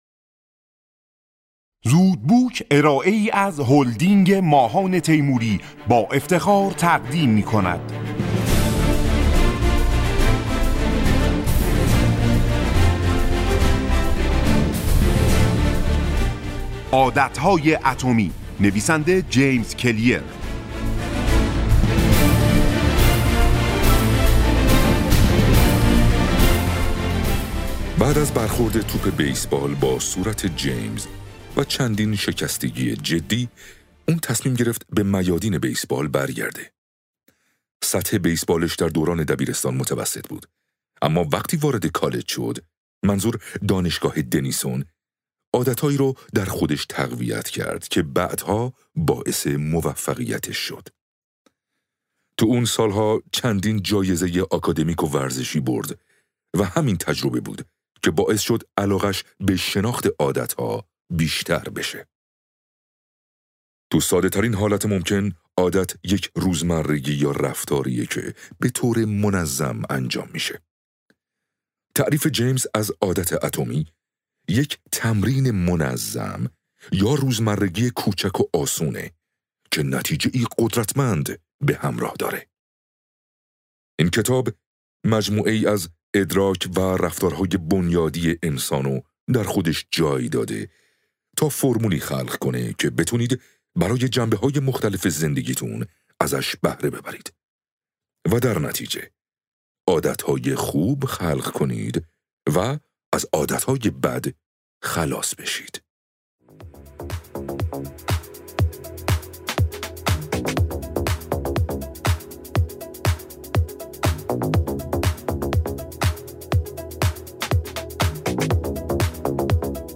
خلاصه کتاب صوتی عادت های اتمی